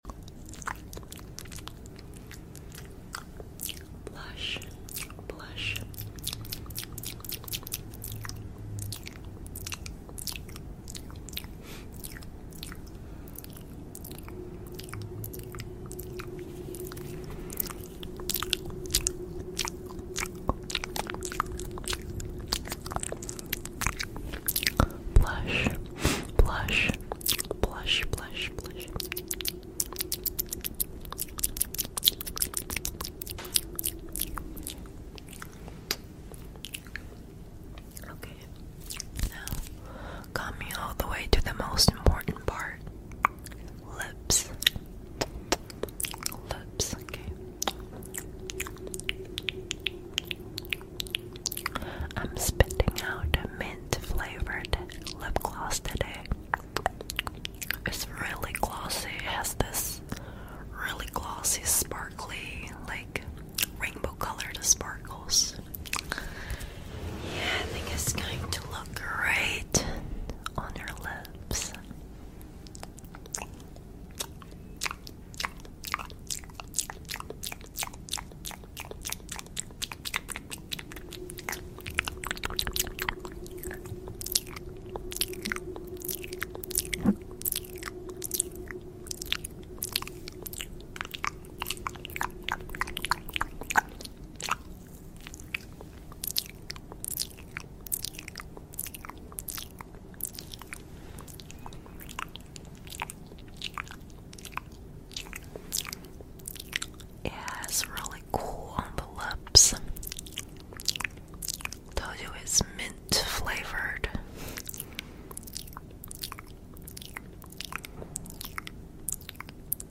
Asmr Spit Painting You ( Sound Effects Free Download